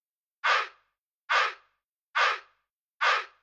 片发生 陷阱包 颂歌 140 BPM
描述：简单的Trap样本包，包括Kick N Snare、HiHats、Synth N Brass和Chants。
标签： 140 bpm Trap Loops Vocal Loops 590.80 KB wav Key : Unknown
声道立体声